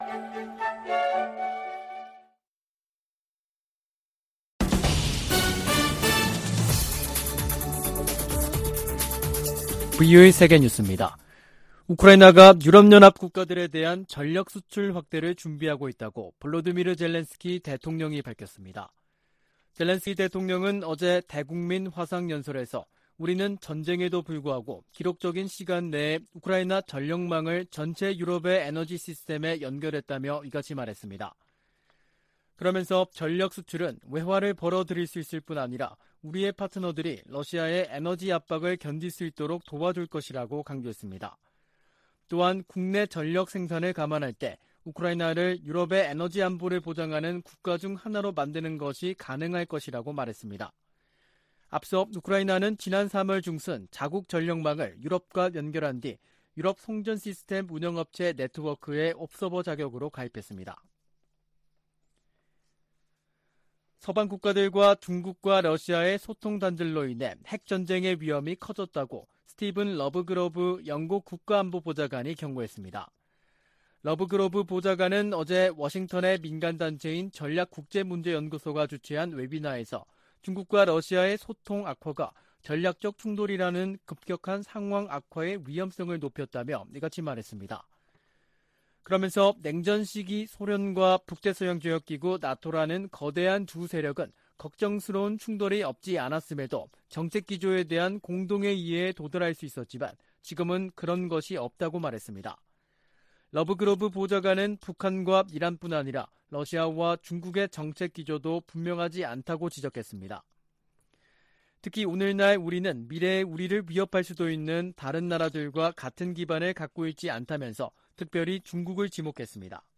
VOA 한국어 간판 뉴스 프로그램 '뉴스 투데이', 2022년 7월 28일 3부 방송입니다. 미국은 북한 정부 연계 해킹조직 관련 정보에 포상금을 두배로 올려 최대 1천만 달러를 지급하기로 했습니다. 북한의 핵 공격 가능성이 예전에는 이론적인 수준이었지만 이제는 현실이 됐다고 척 헤이글 전 미 국방장관이 평가했습니다. 김정은 북한 국무위원장이 미국과 한국을 강력 비난하고, 미국과의 군사적 충돌에 철저히 준비할 것을 다짐했다고 관영 매체들이 보도했습니다.